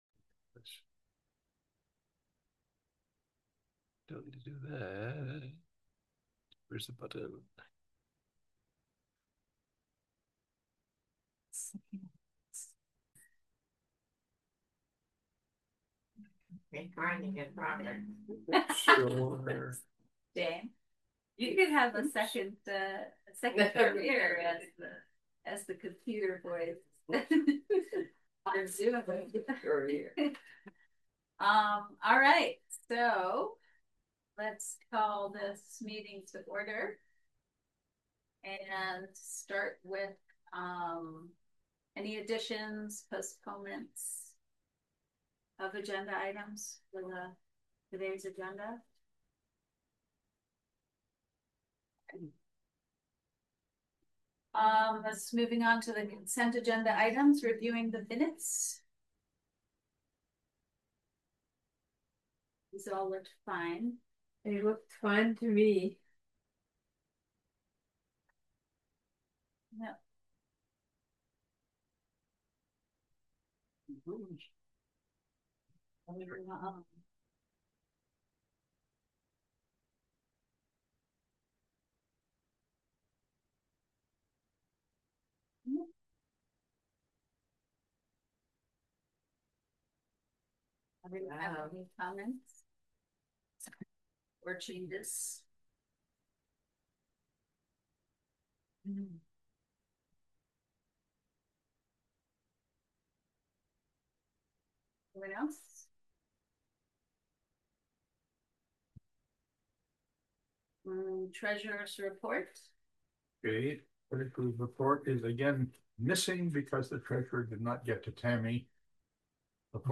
July 10 2025: RFL Board Meeting - Roxbury Free Library
Agenda Packet Meeting Minutes {APPROVED} Meeting Minutes {AUDIO} Roxbury Free Library Board of Trustee’s Meeting July 10 2025 at 9:00 Physical Meeting location: Roxbury Free Library, 1491 Roxbury Rd., Roxbury VT.